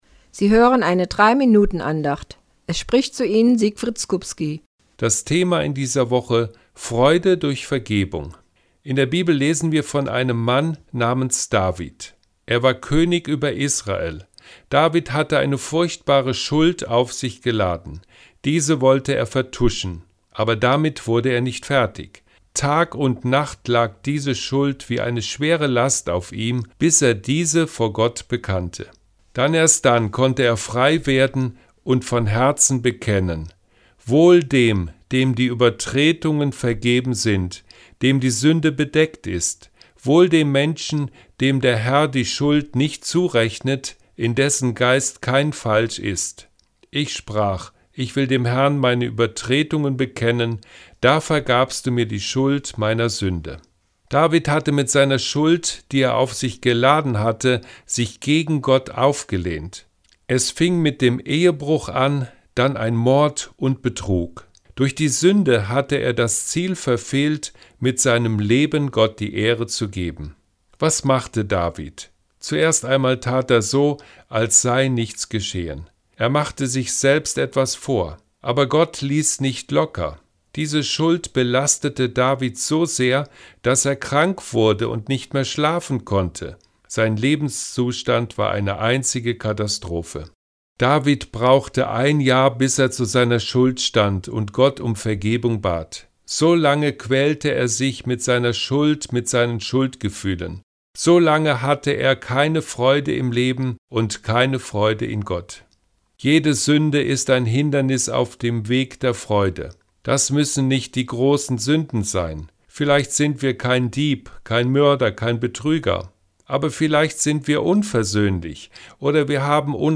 WEB-ANDACHT